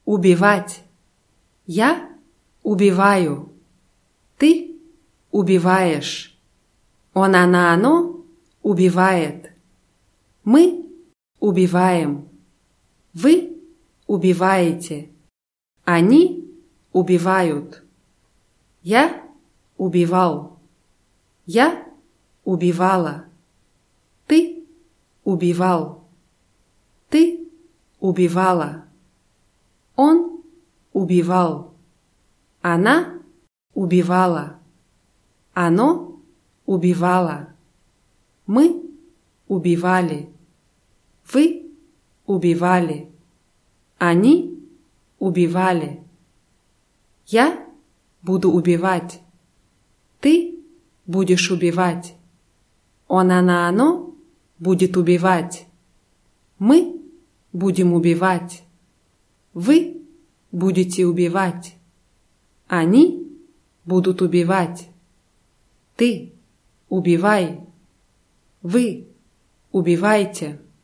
убивать [ubʲiwátʲ]